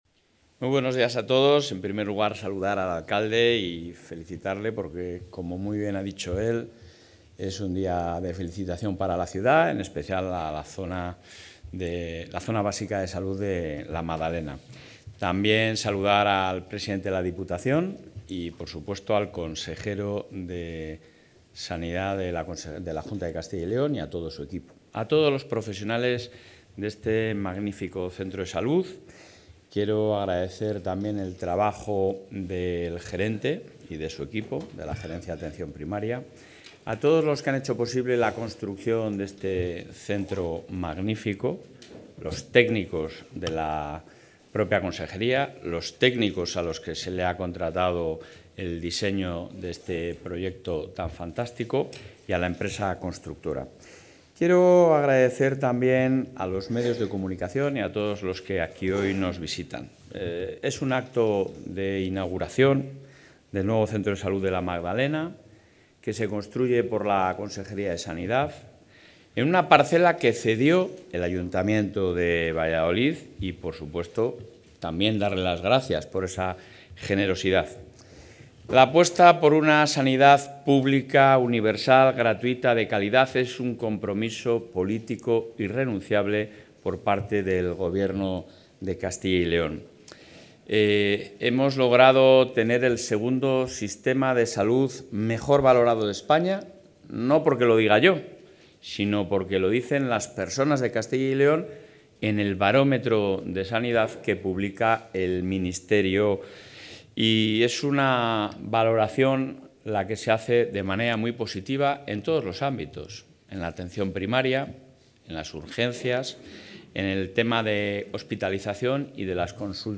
Intervención del presidente de la Junta.
Durante su intervención en la inauguración del centro de salud La Magdalena, en Valladolid, que comenzará su actividad el próximo martes, el presidente de la Junta de Castilla y León, Alfonso Fernández Mañueco, ha puesto a estas instalaciones como ejemplo del esfuerzo inversor del Ejecutivo autonómico en infraestructuras asistenciales y equipamientos sanitarios modernos, con la mejor tecnología.